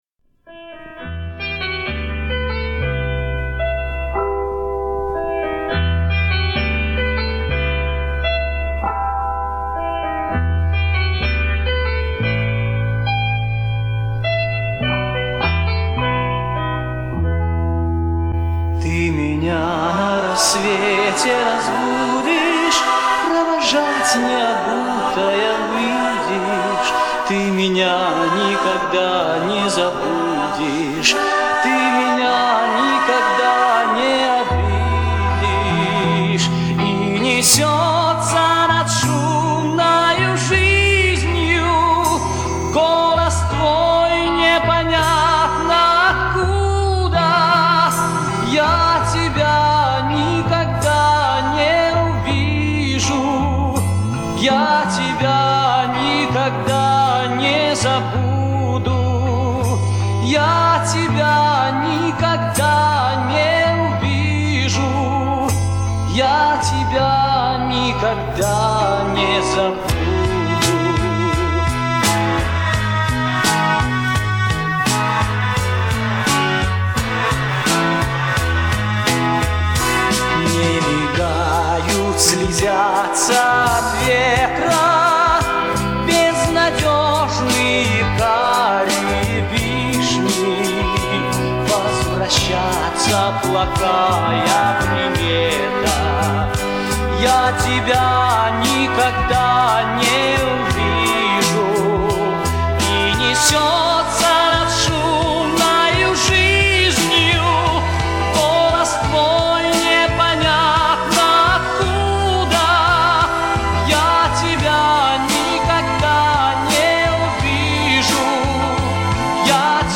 Похоже , что так песня записана